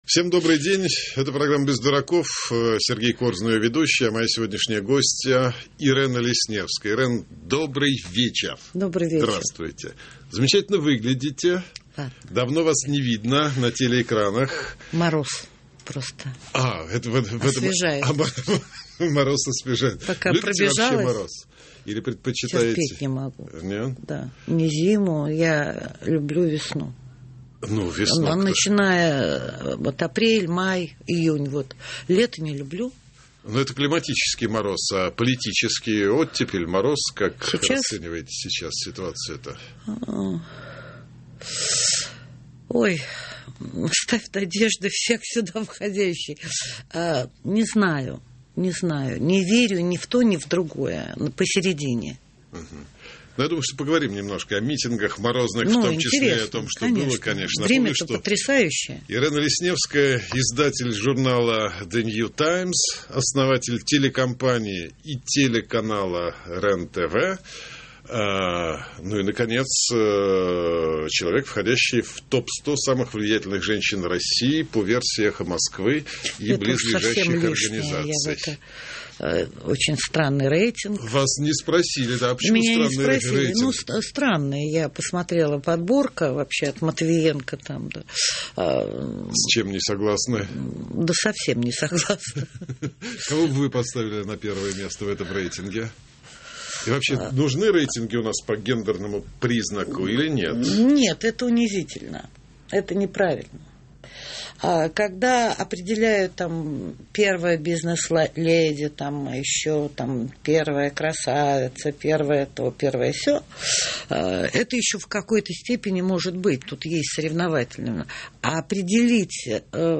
Интервью из архива «Эха Москвы» от 12.02.2012